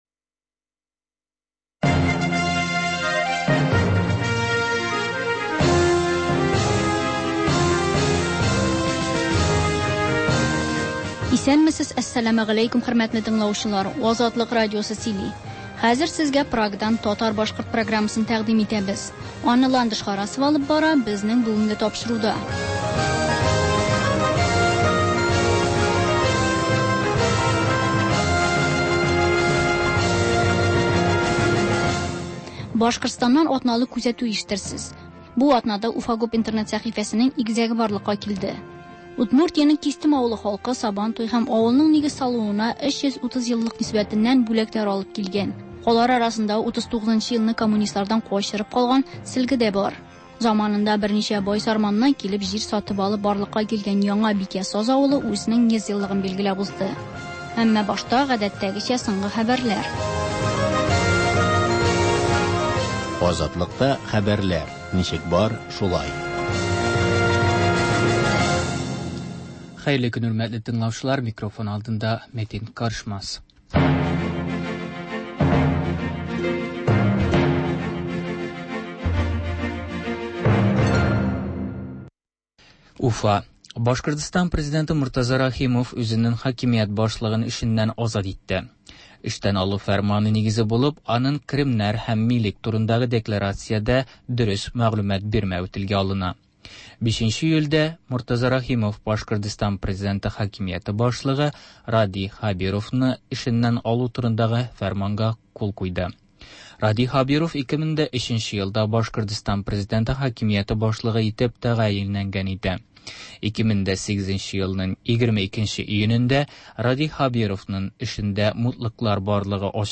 Азатлык радиосы бар атнага күз сала - соңгы хәбәрләр - Башкортстаннан атналык күзәтү - түгәрәк өстәл артында сөйләшү